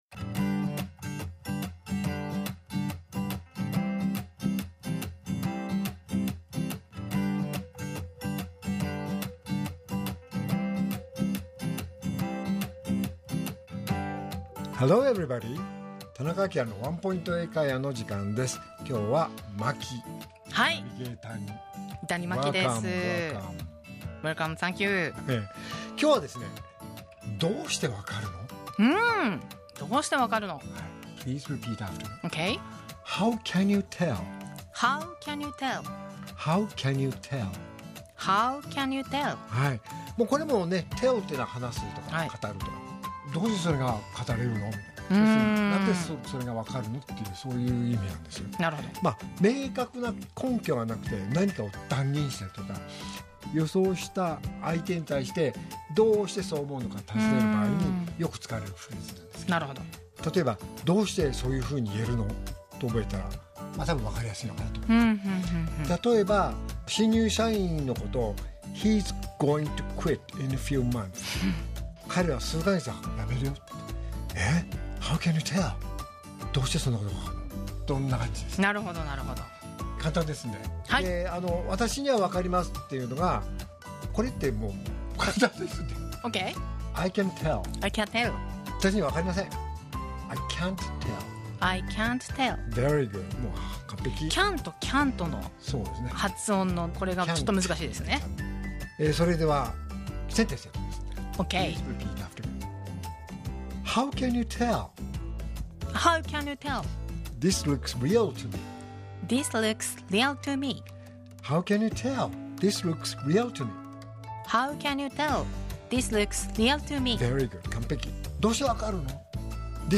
R7.10 AKILA市長のワンポイント英会話